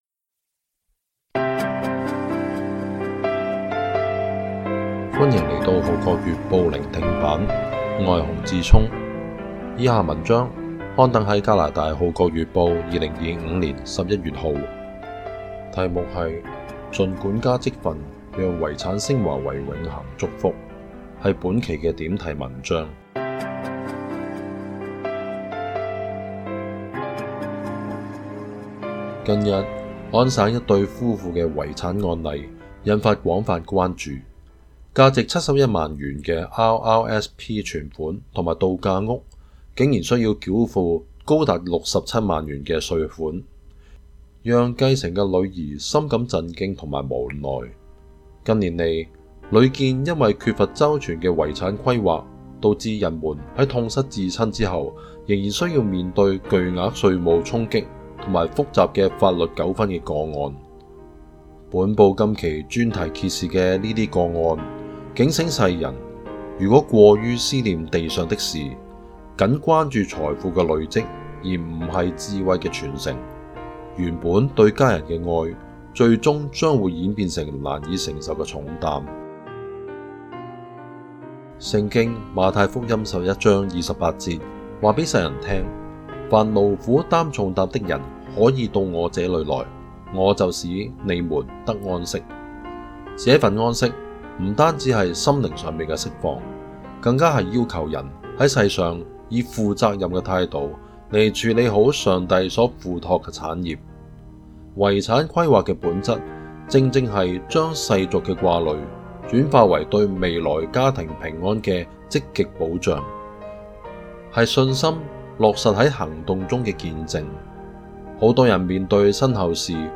聆聽版/Audio盡管家職分讓遺產昇華為永恆祝福 點題